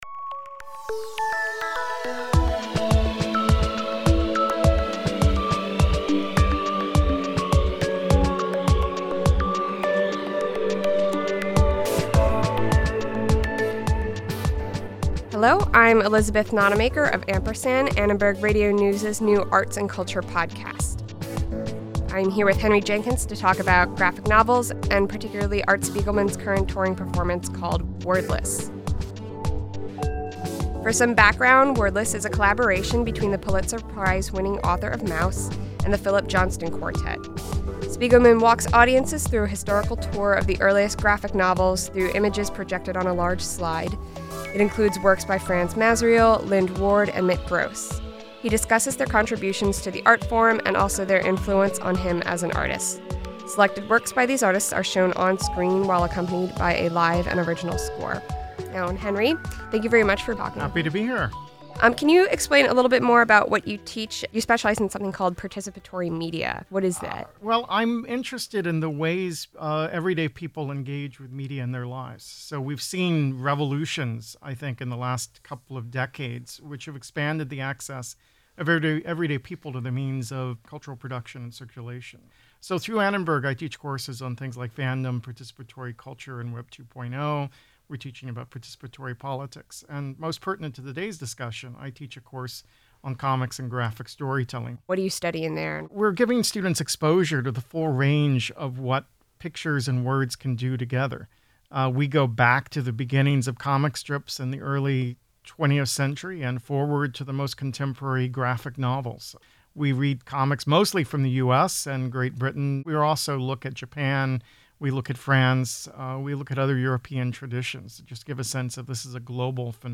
sat down with professor and media scholar Henry Jenkins